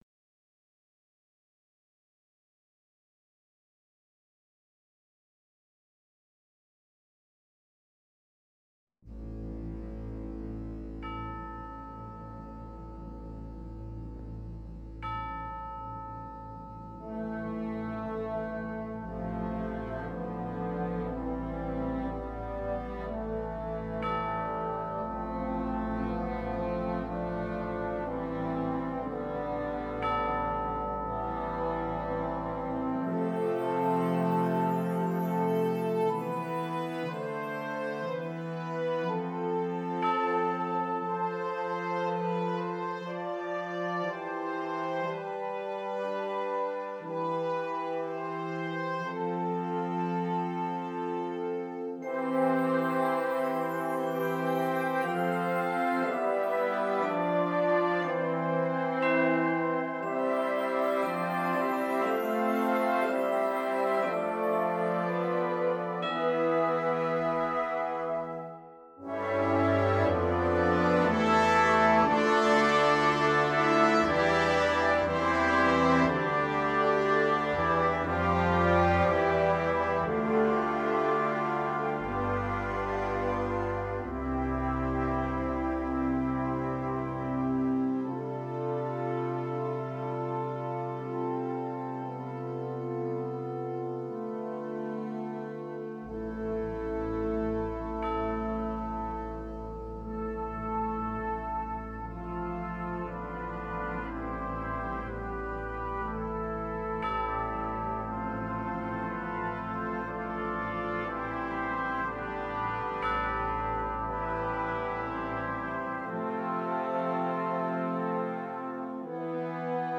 concert band setting